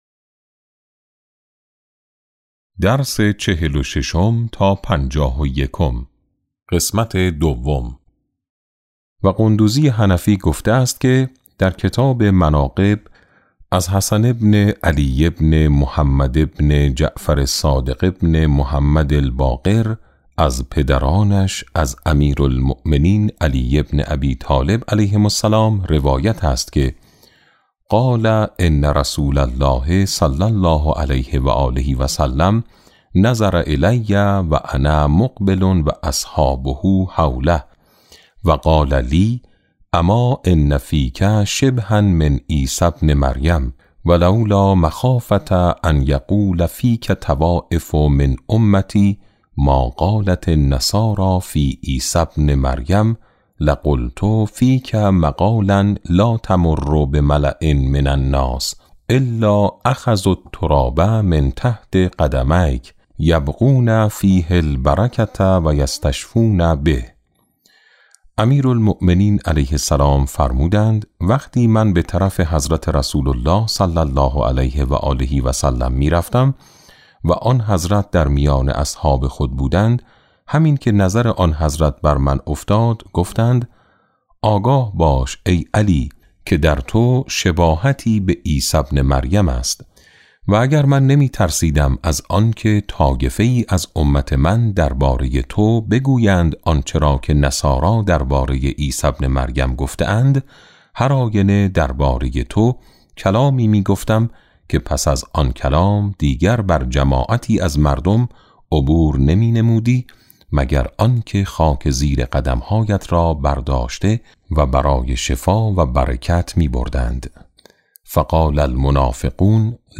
کتاب صوتی امام شناسی ج4 - جلسه3